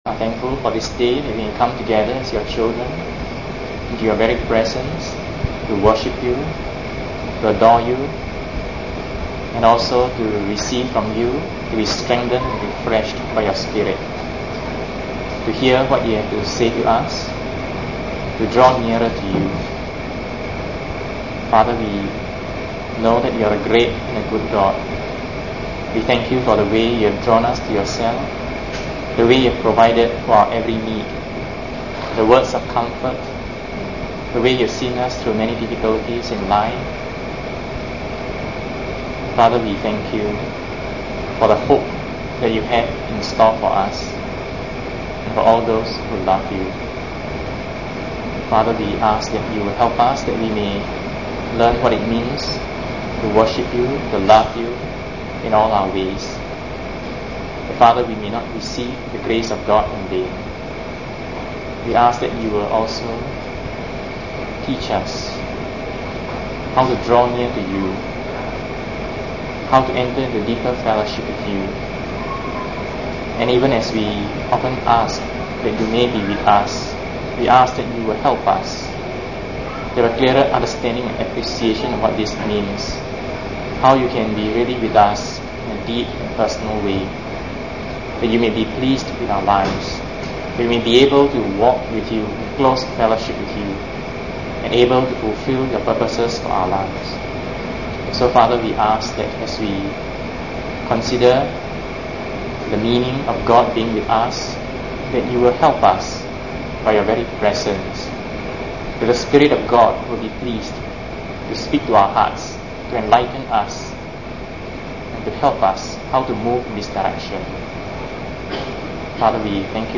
Preached: 15 Feb 87